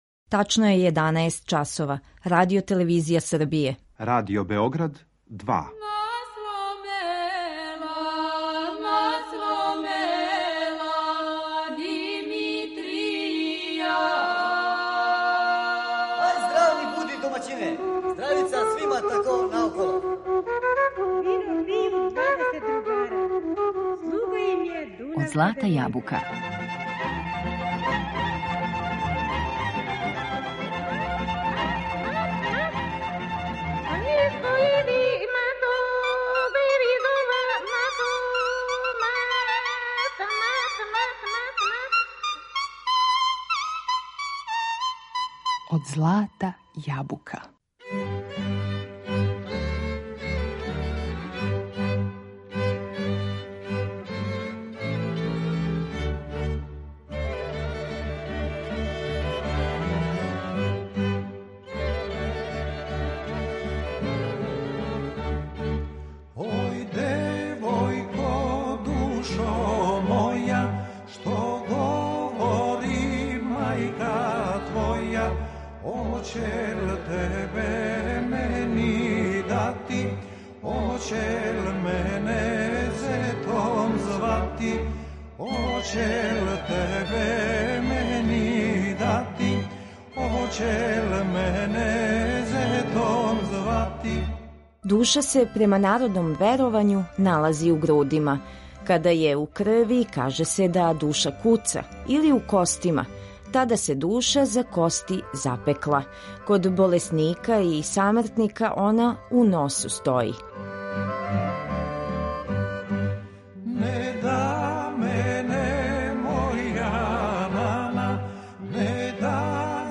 У данашњем издању емисије Од злата јабука говоримо о души у народној култури, уз најлепше љубавне песме.